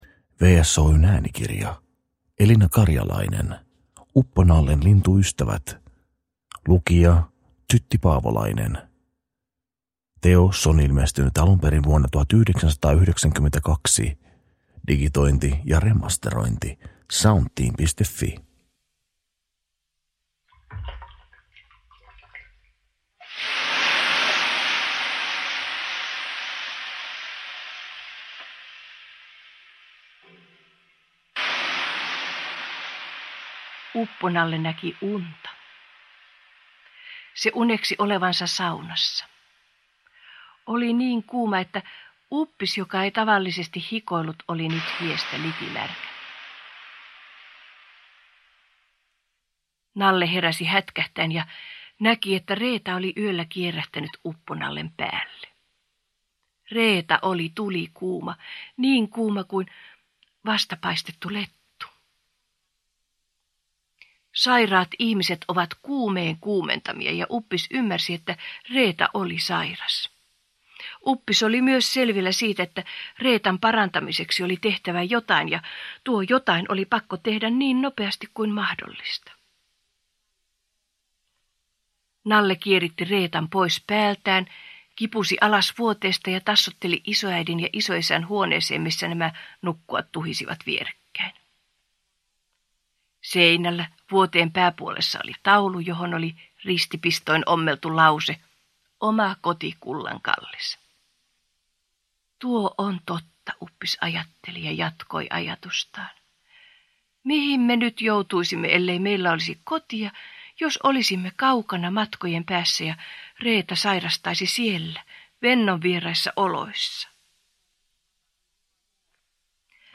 Uppo-Nallen lintuystävät – Ljudbok – Laddas ner